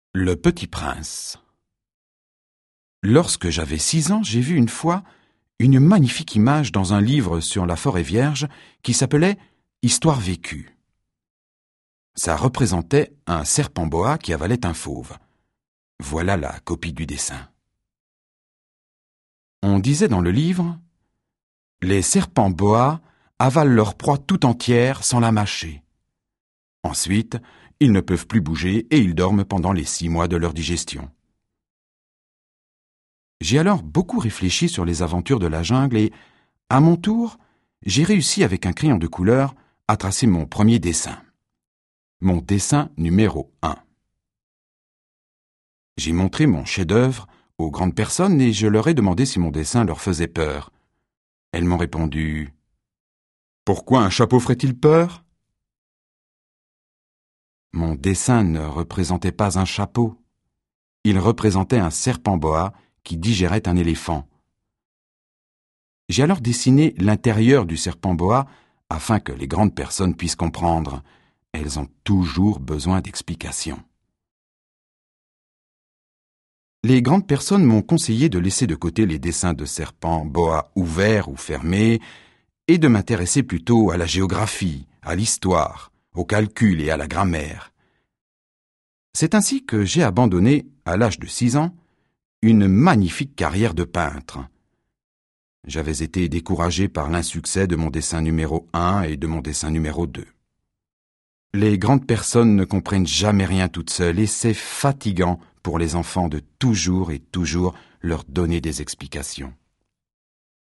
Das Hörbuch zum Sprachen lernen.Ungekürzte Originalfassung / 2 Audio-CDs + Textbuch + CD-ROM
Interaktives Hörbuch Französisch